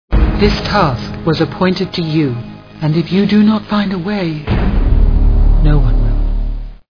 Lord of the Rings Movie Sound Bites